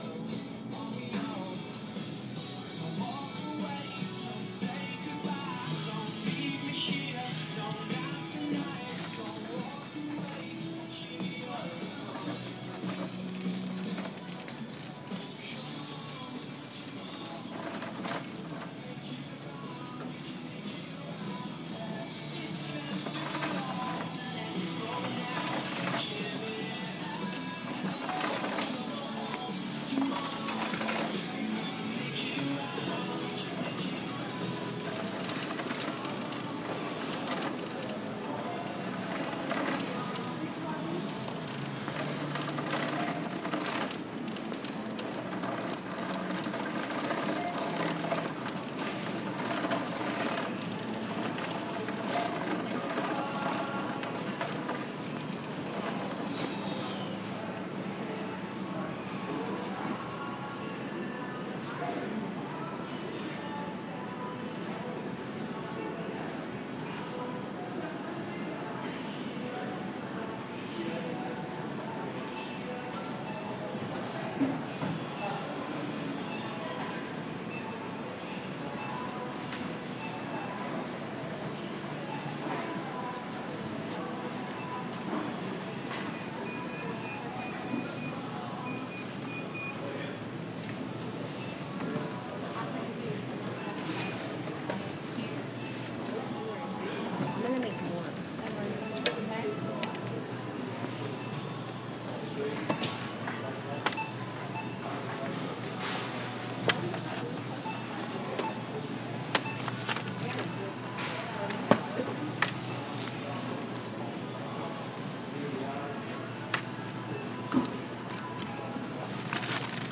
Muzak for shopping